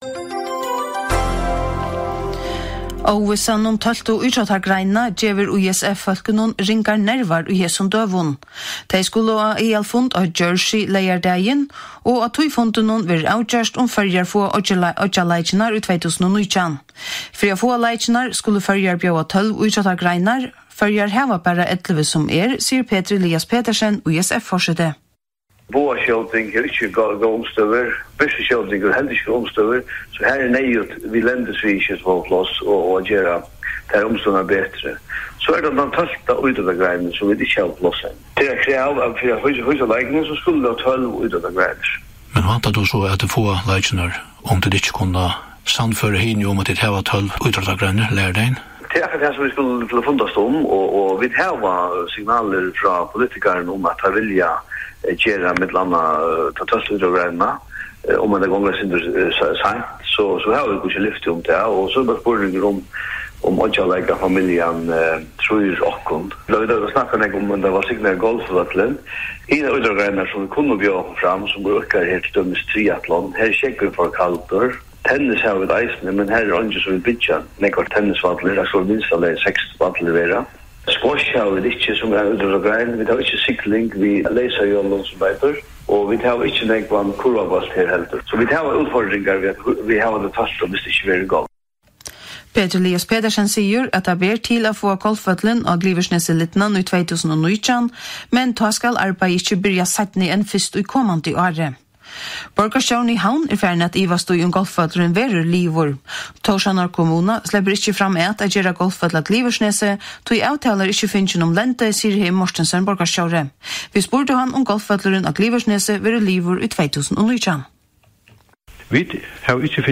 Prát